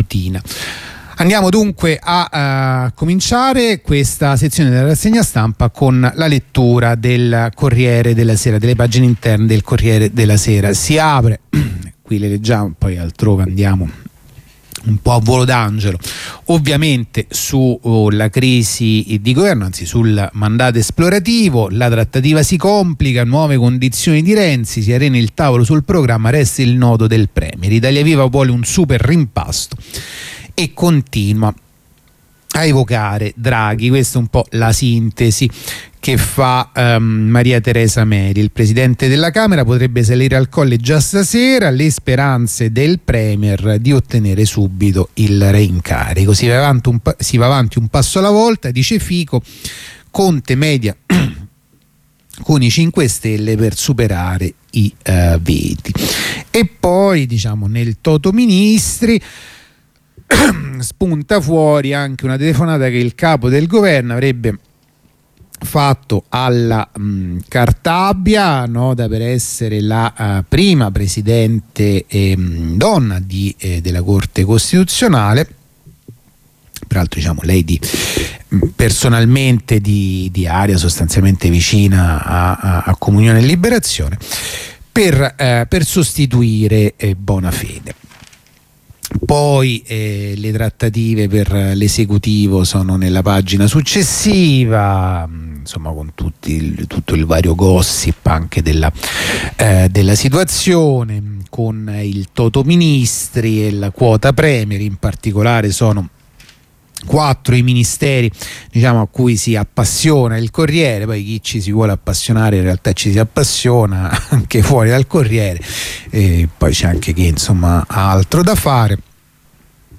La rassegna stampa andata in onda martedì 2 febbraio 2021